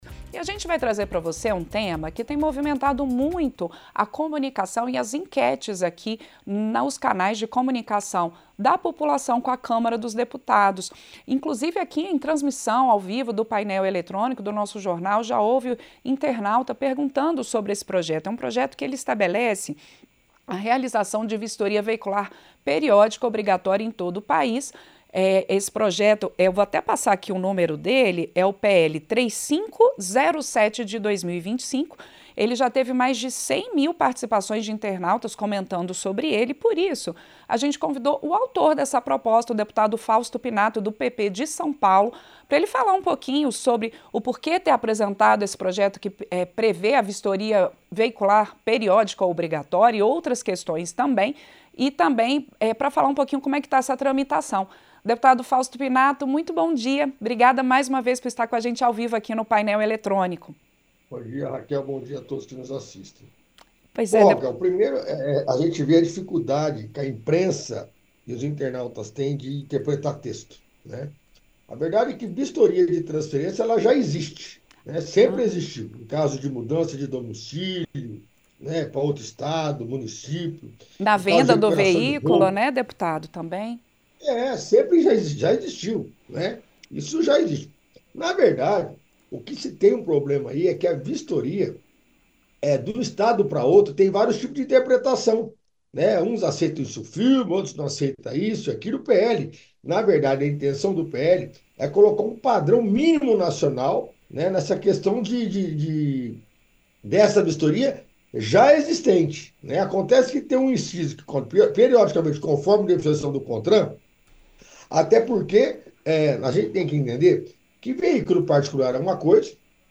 O deputado Fausto Pinato (PP-SP) respondeu às críticas que projeto de sua autoria tem sofrido nas redes sociais. A proposta (PL 3507/25) institui vistoria veicular periódica obrigatória e já recebeu mais de 100 mil comentários nas enquetes feitas pela Câmara dos Deputados a respeito do tema; com maioria contra o texto.
Entrevista - Dep. Fausto Pinato (PP-SP)